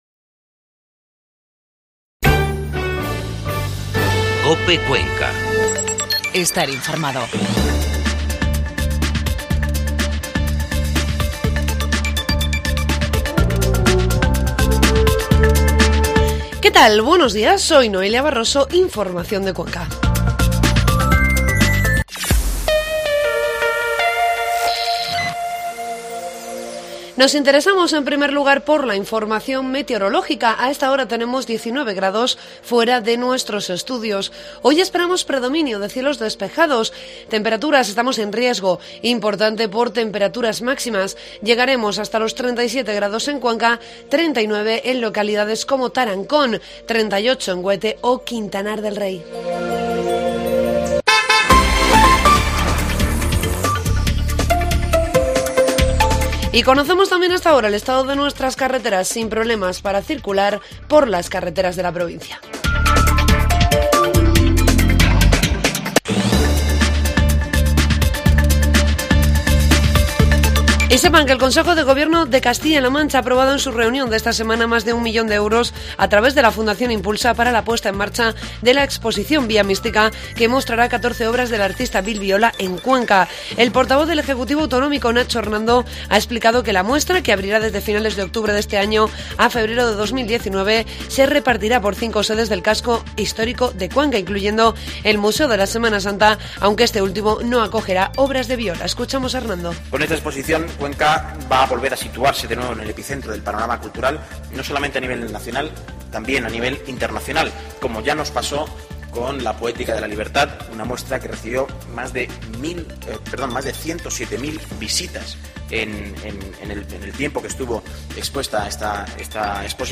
Informativo matinal COPE Cuenca 2 de agosto